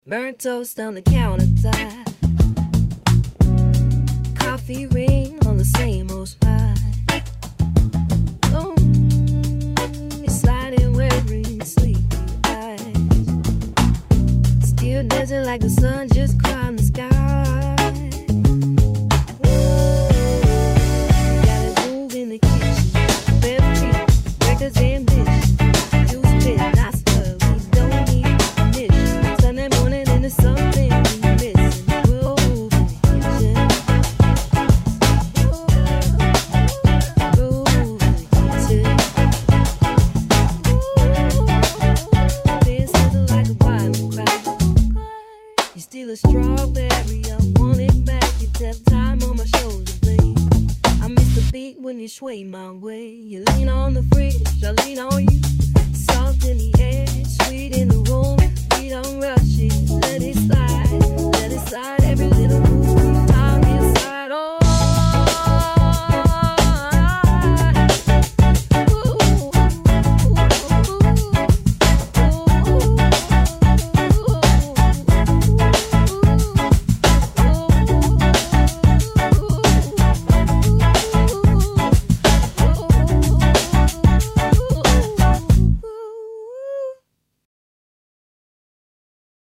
ボーカルトラックのキャラクターを変えてみる
しばらくすると、異なるボイスキャラクターによるメロディートラックが作成されます。
元のボーカルトラックをミュートして、ボーカルを差し替えると、次のような曲になりました。